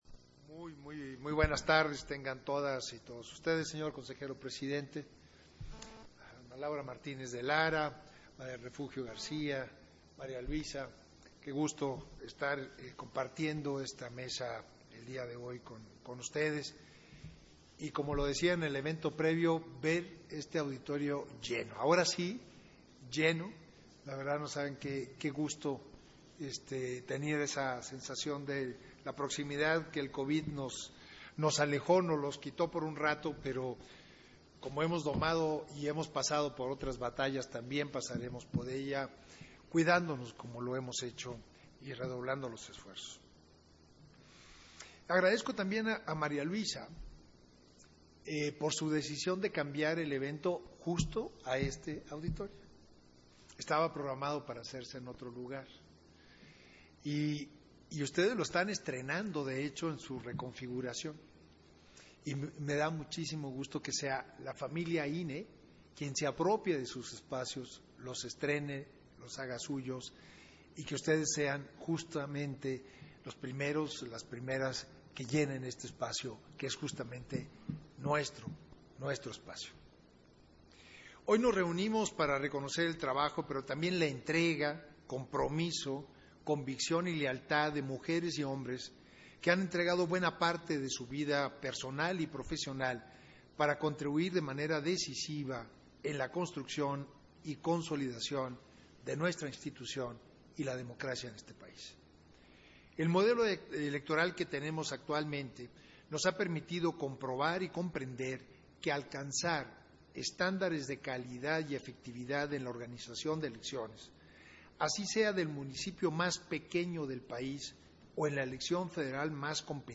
Intervención de Edmundo Jacobo Molina, en la ceremonia de entrega de reconocimientos y medallas al personal incorporado al Programa de Retiro 2021